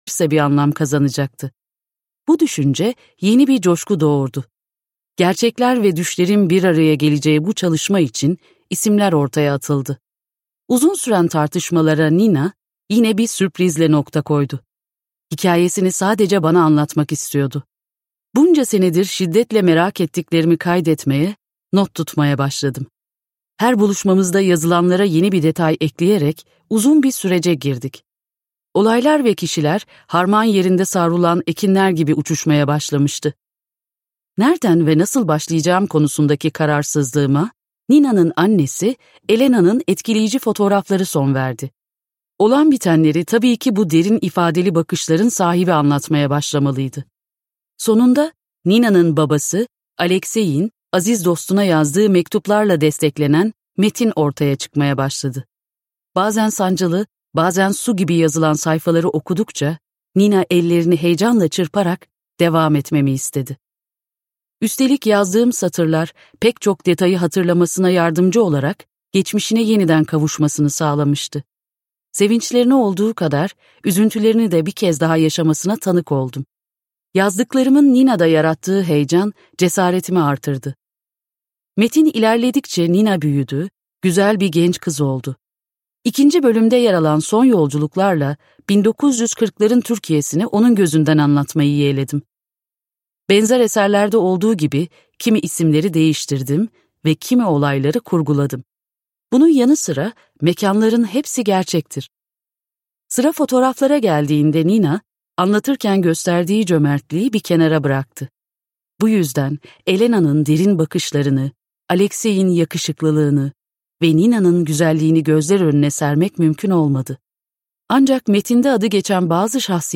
Matmazel Nina - Seslenen Kitap